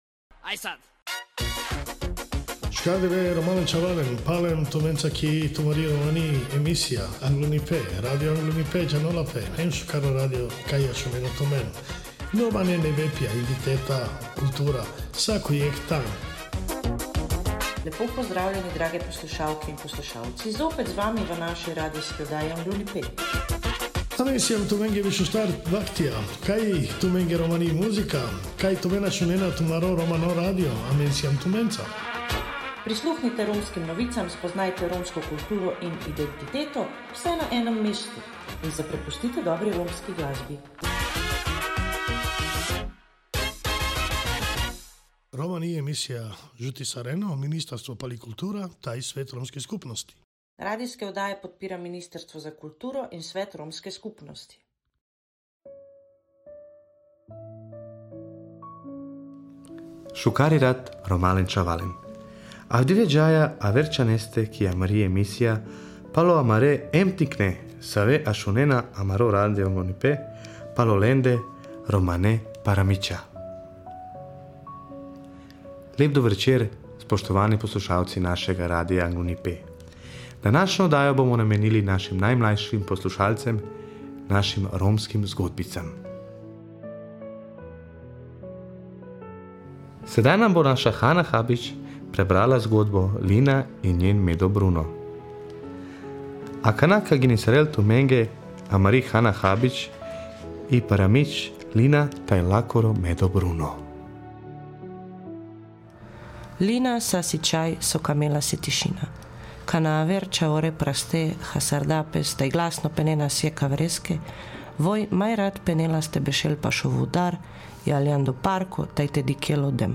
Otroška oddaja Romskih pravljic. https